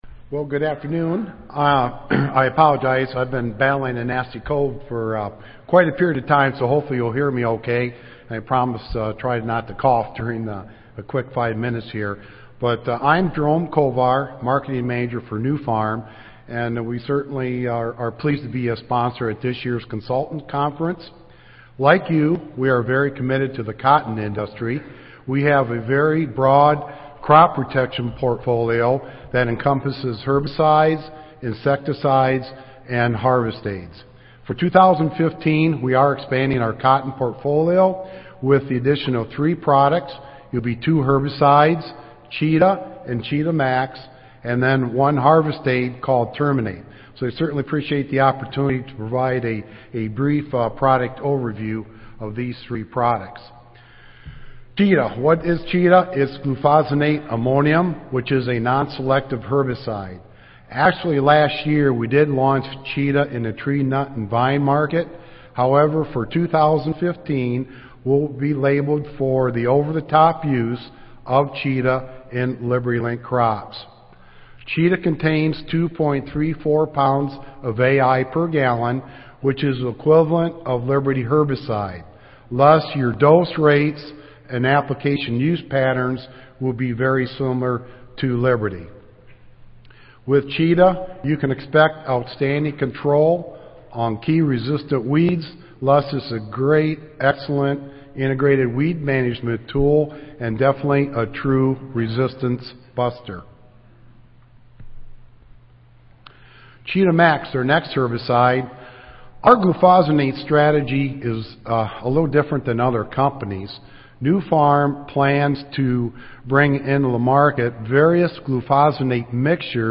Cotton Consultants Conference - Update from Sponsors
Audio File Recorded Presentation